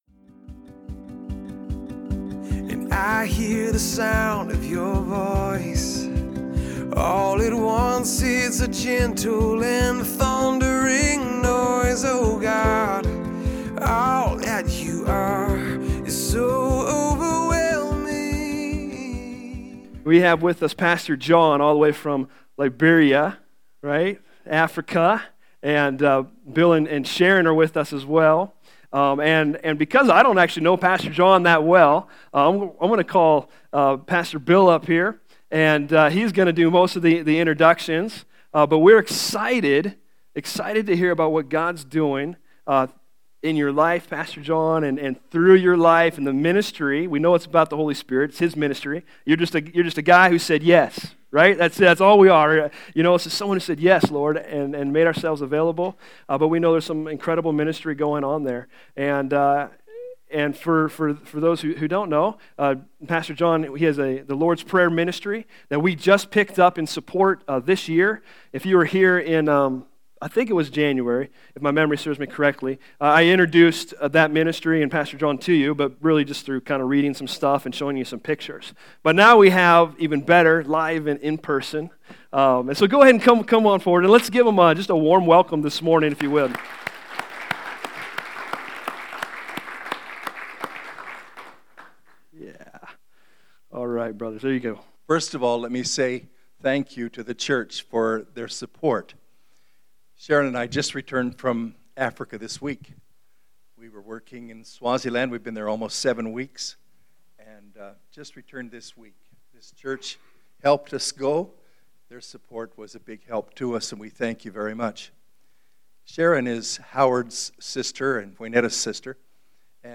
Series: Missionary Speaker